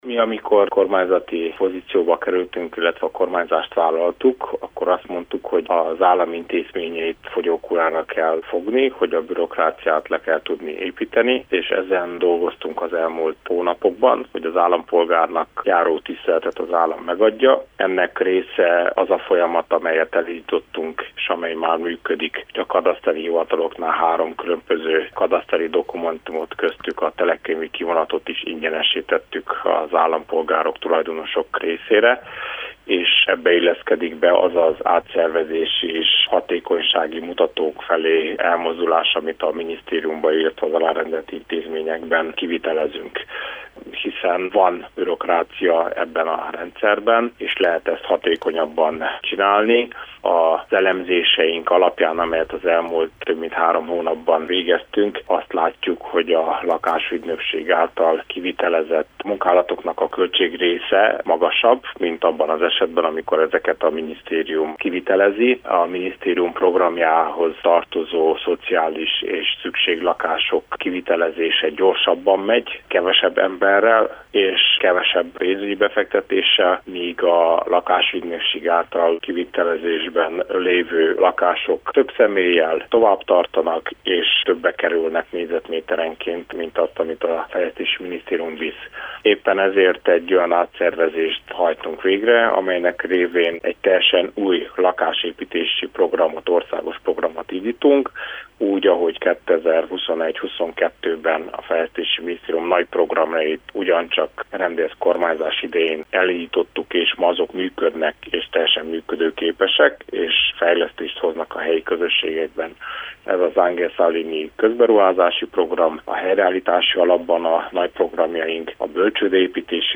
Erre mondják, hogy saját jövedelem, de valójában ez egy állami költségvetésből származó pénzügyi forrás – magyarázta állítását a Kolozsvári Rádiónak Cseke Attila fejlesztési miniszter, aki a beszélgetés során az intézmény hatékonyságát is megkérdőjelezte.